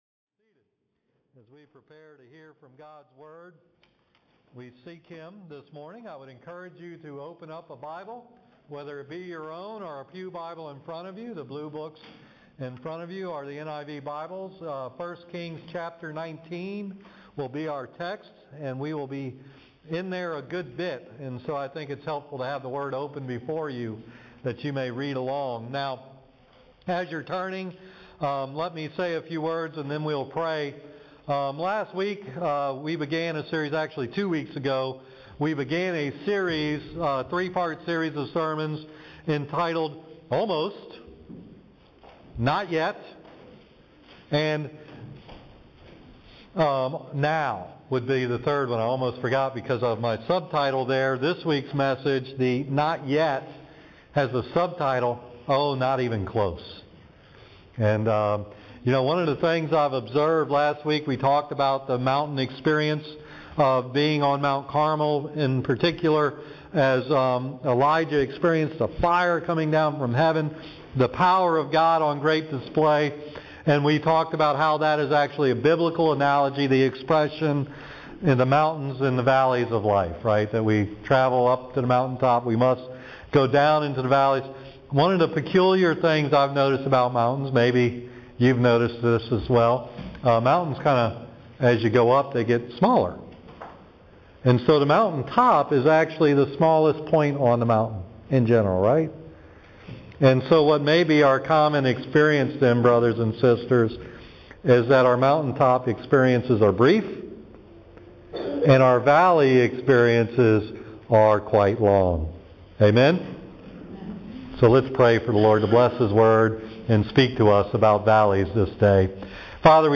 Back with a Bang: Sermon “Almost”